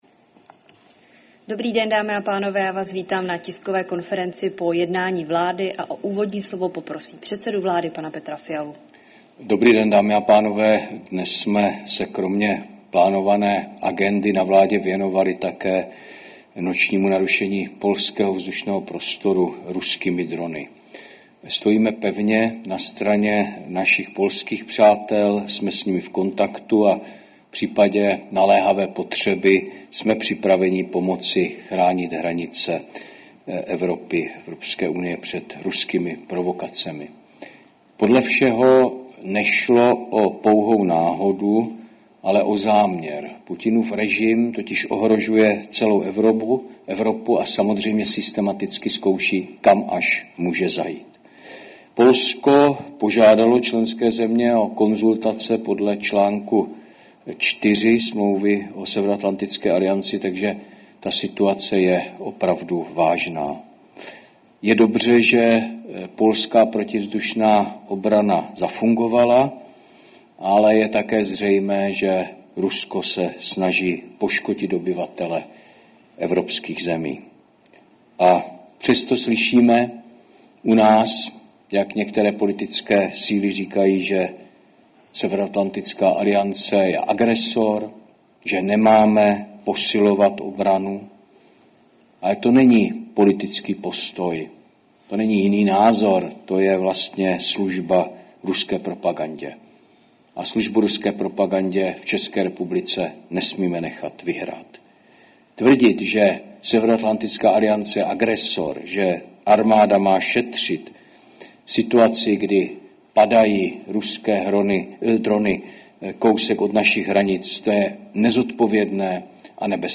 Tisková konference po jednání vlády, 10. září 2025